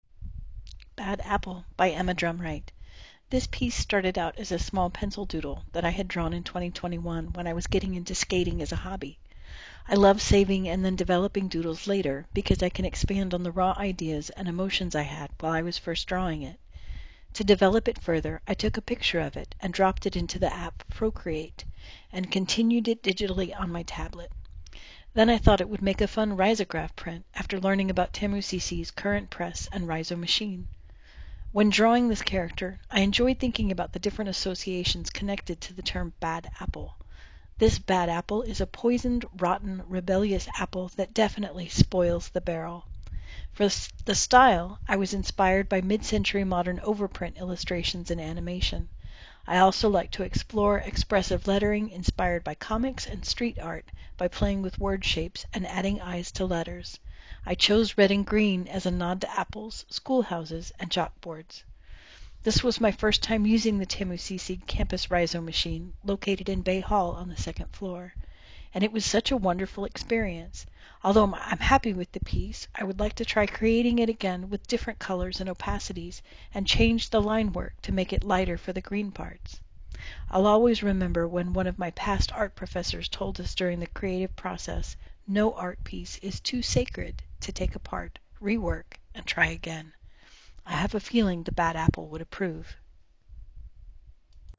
Audio recording of artist statement